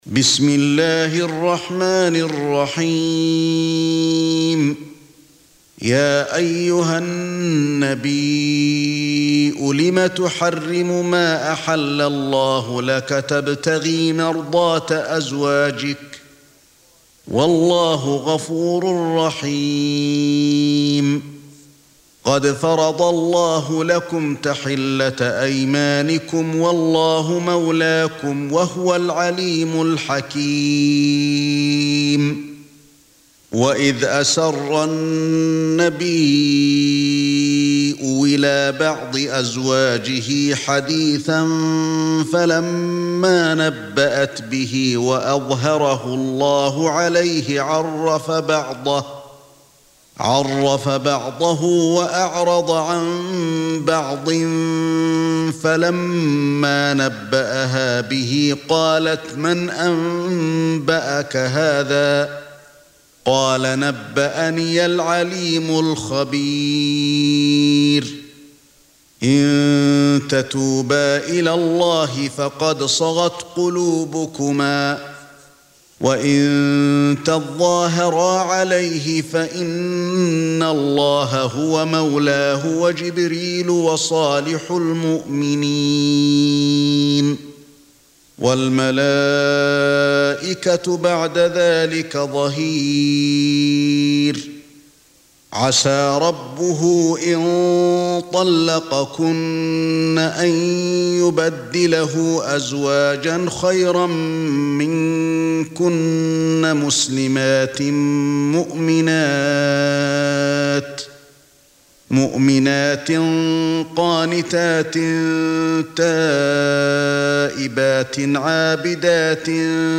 66. Surah At-Tahr�m سورة التحريم Audio Quran Tarteel Recitation
Hafs for Assem